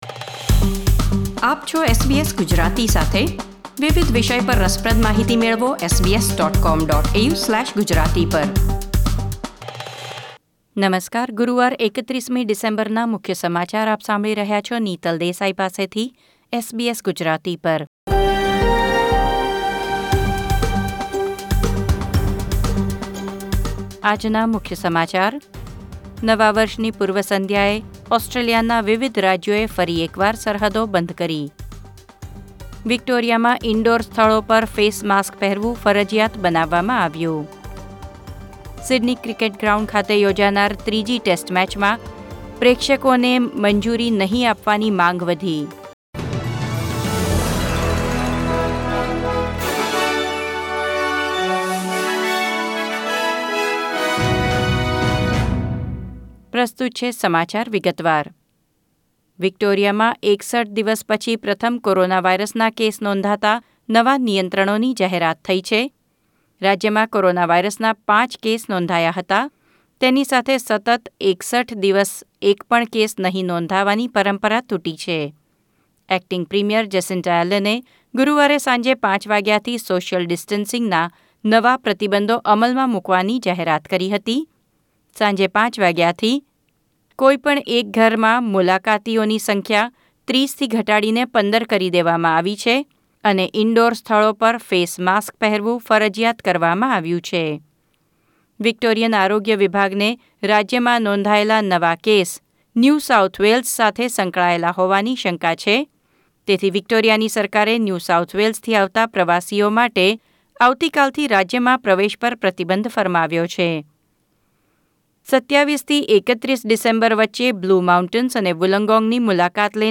SBS Gujarati News Bulletin 31 December 2020